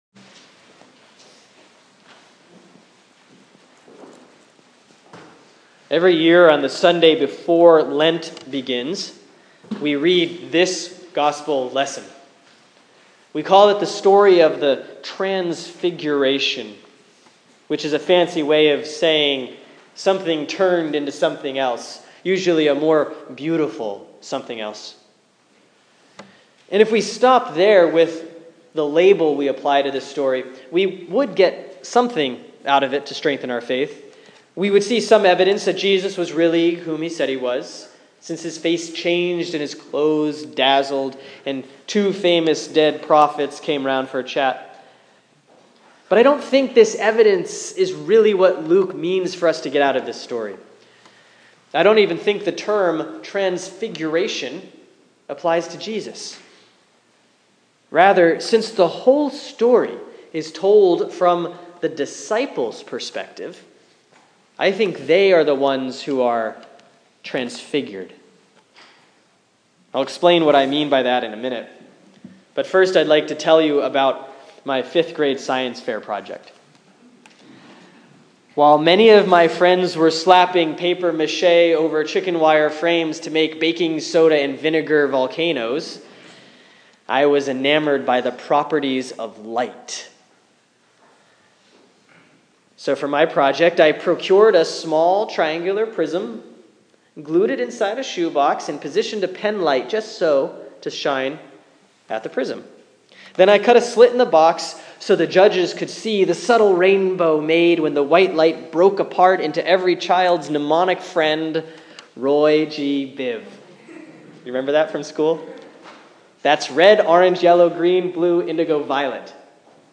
Sermon for Sunday, February 7, 2016 || Last Epiphany C || Luke 9:28-36